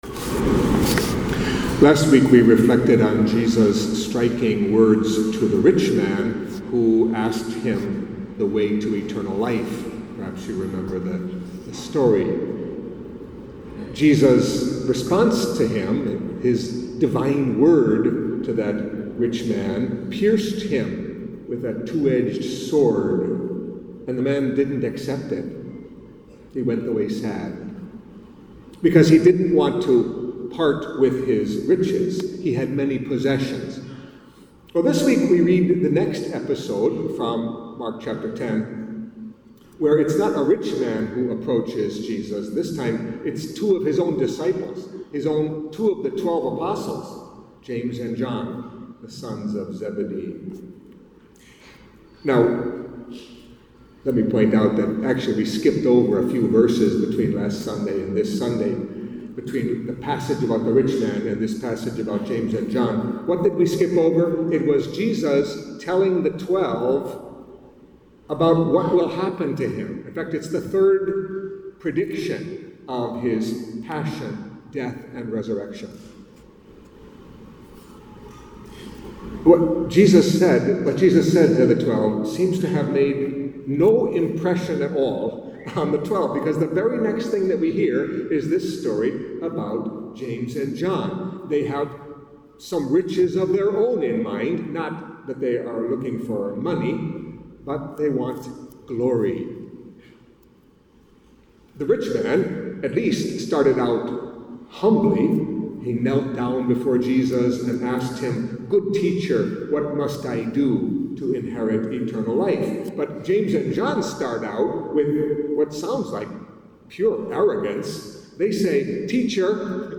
Catholic Mass homily for the Twenty-Ninth Sunday in Ordinary Time